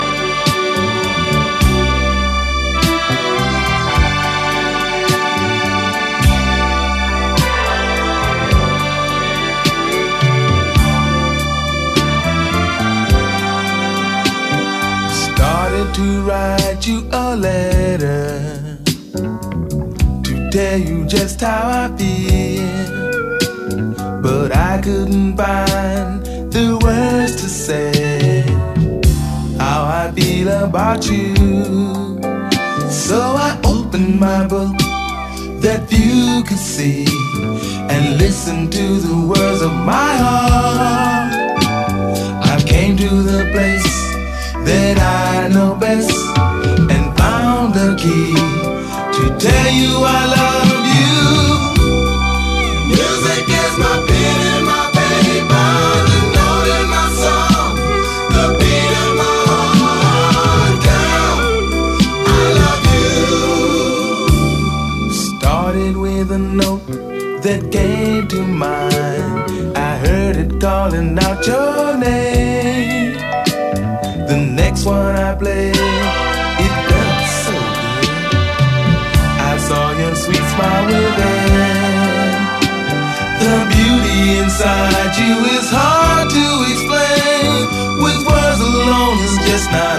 ジャンル(スタイル) SOUL / FUNK / DISCO